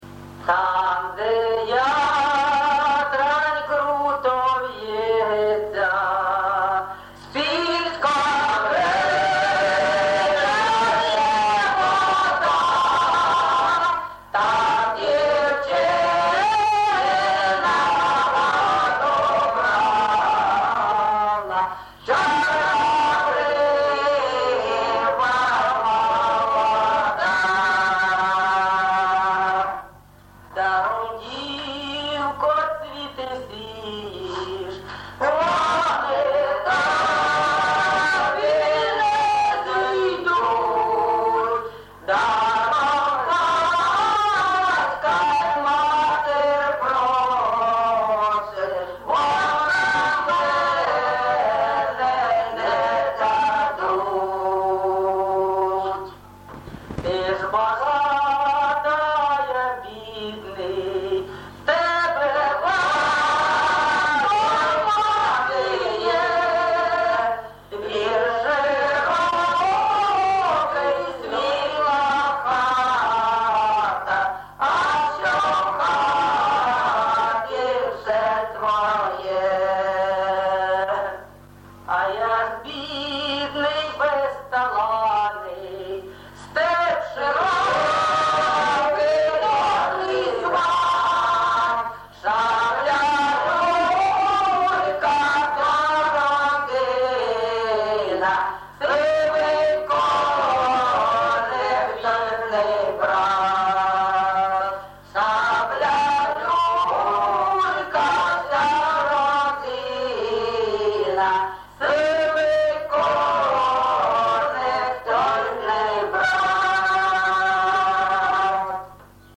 ЖанрПісні літературного походження
Місце записум. Єнакієве, Горлівський район, Донецька обл., Україна, Слобожанщина